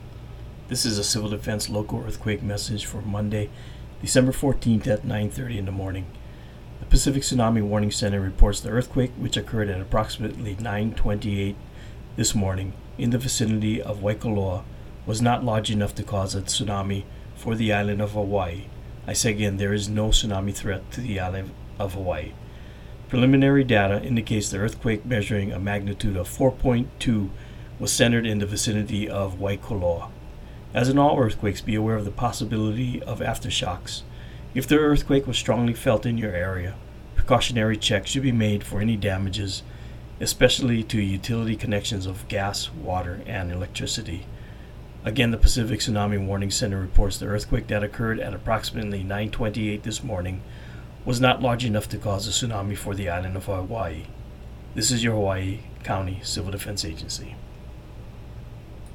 From the Hawaiʻi County Civil Defense: